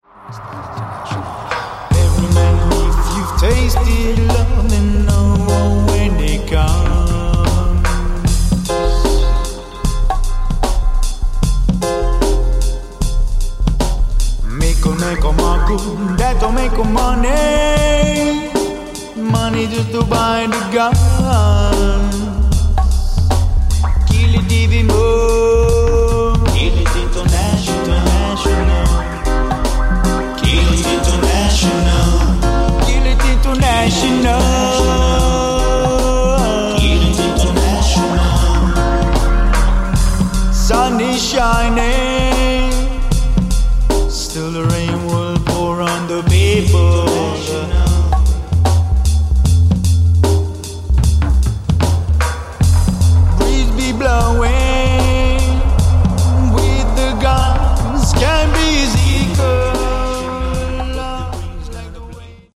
Roots - Reggae